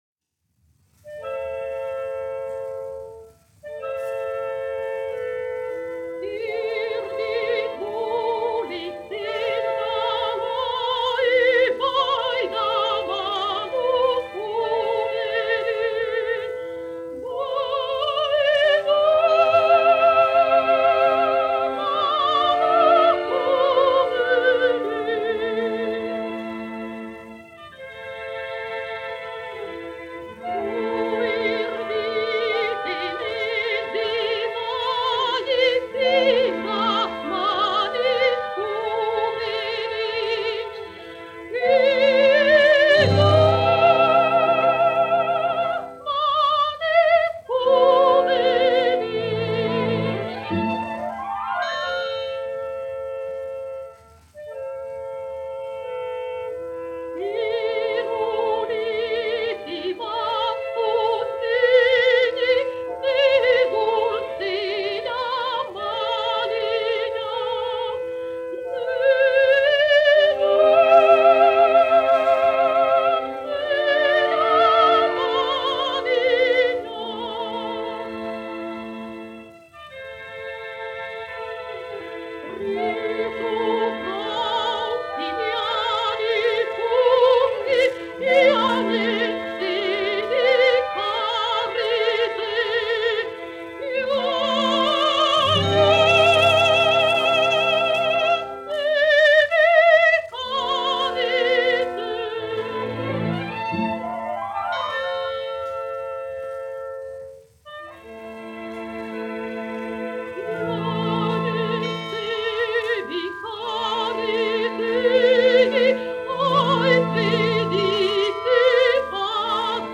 1 skpl. : analogs, 78 apgr/min, mono ; 25 cm
Dziesmas (vidēja balss) ar orķestri
Latvijas vēsturiskie šellaka skaņuplašu ieraksti (Kolekcija)